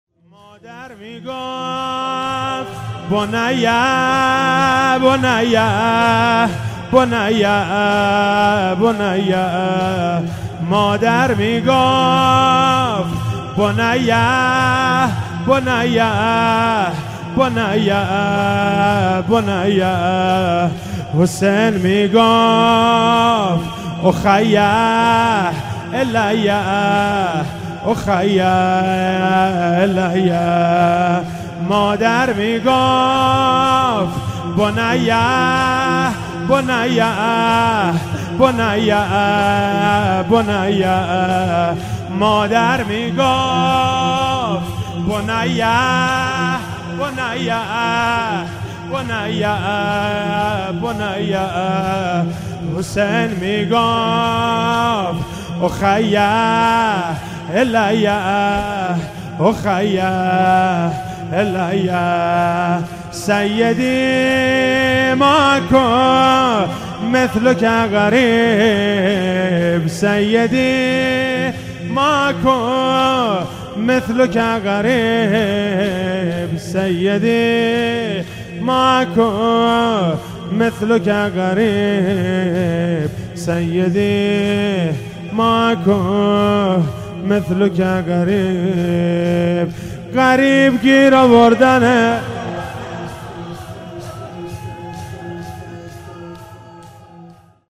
فاطمیه 96 - شب پنجم - شور - مادر میگفت بنی بنی بنی